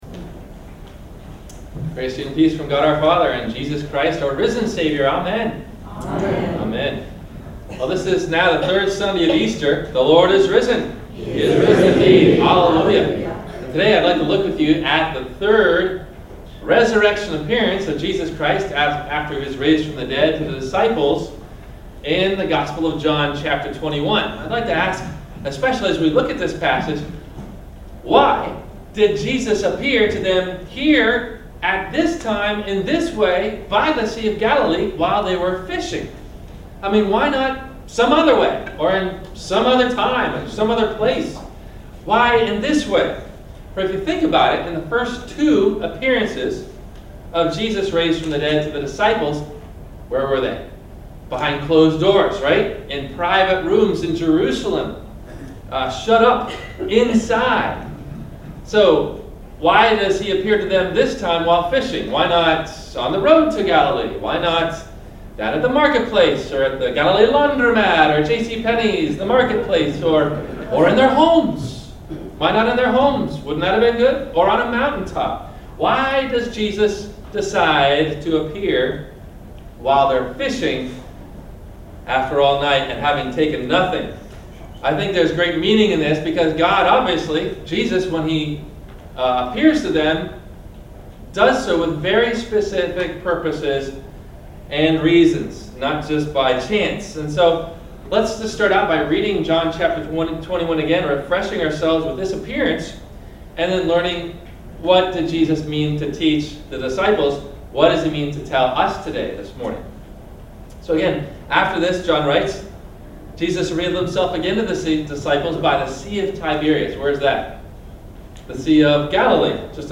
Questions that will be answered in the Sermon: